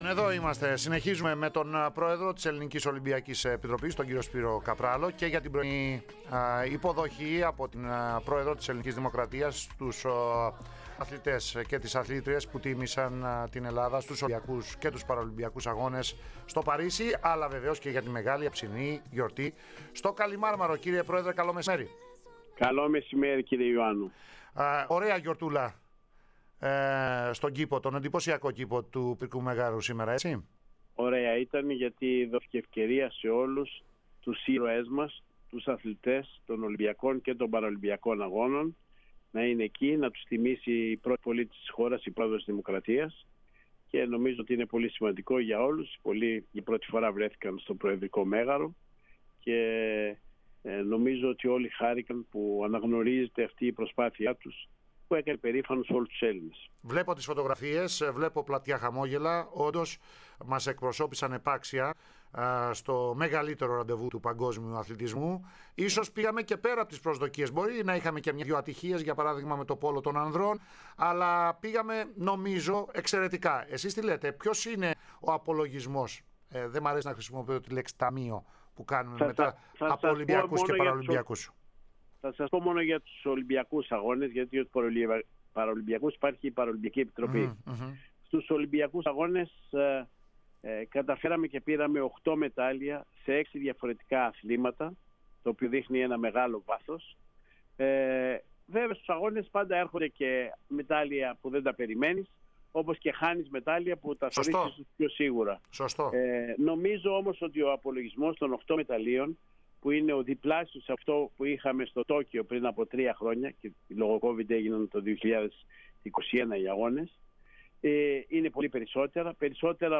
Λίγες ώρες πριν την μεγάλη γιορτή του ελληνικού αθλητισμού στο Παναθηναϊκό Στάδιο (19:30), κατά την οποία θα τιμηθούν οι Ολυμπιονίκες της γαλλικής πρωτεύουσας, αλλά κι αυτοί των Αγώνων που έγιναν στην Αθήνα πριν από 20 χρόνια, ο Σπύρος Καπράλος μίλησε στην ΕΡΑ ΣΠΟΡ.